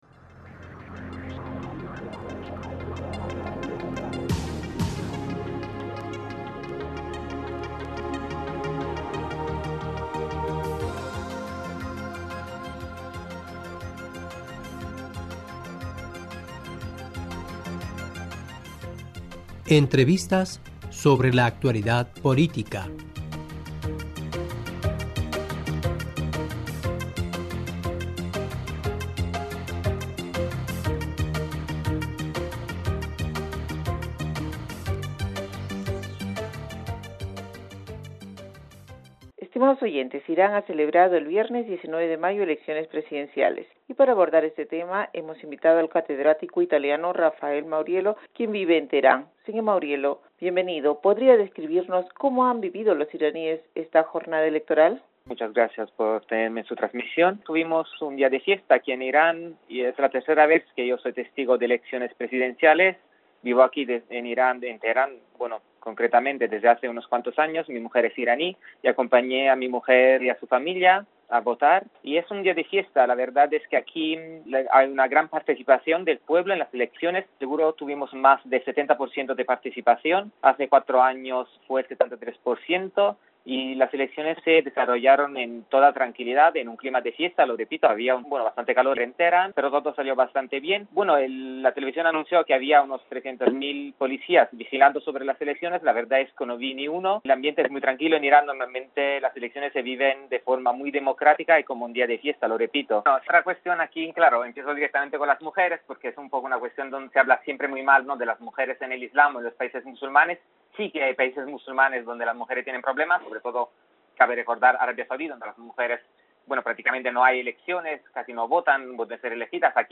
Y para abordar este tema hemos invitado al catedrático italiano